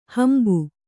♪ hambu